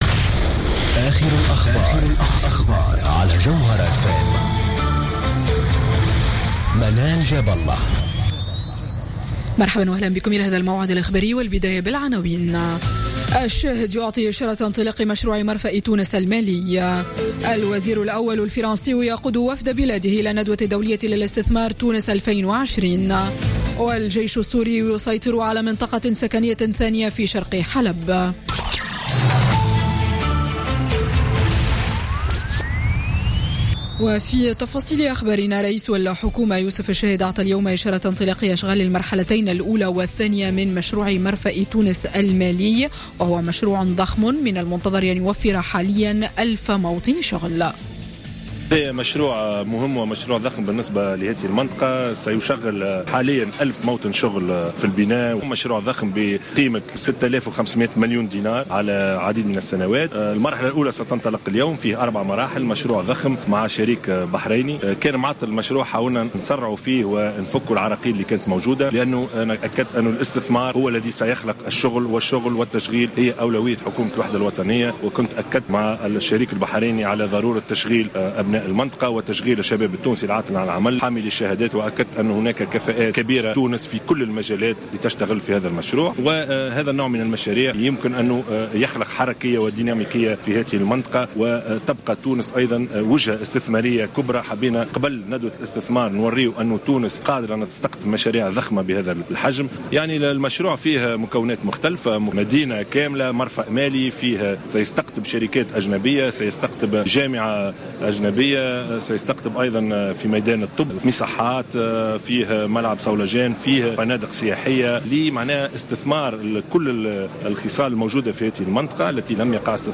Journal Info 19h00 du dimanche 27 novembre 2016